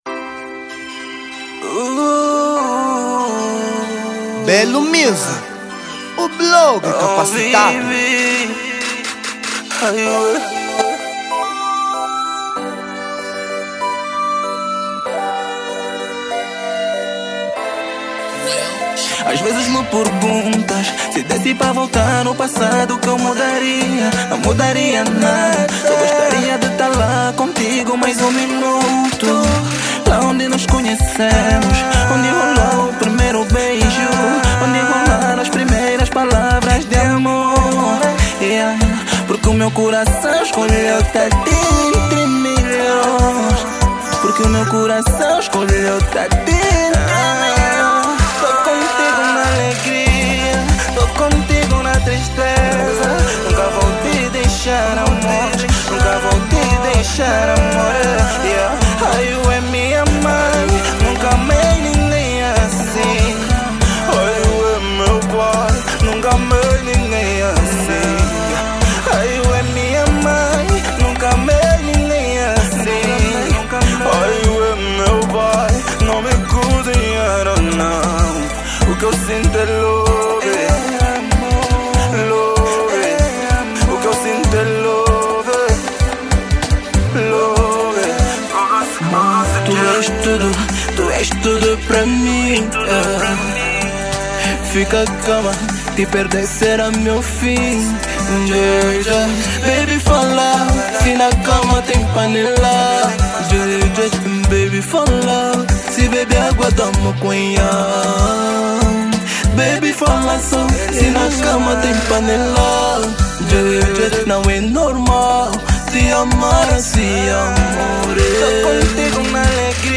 Género: Zouk